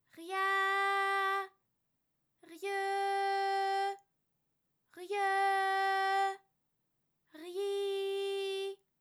ALYS-DB-002-FRA - First publicly heard French UTAU vocal library of ALYS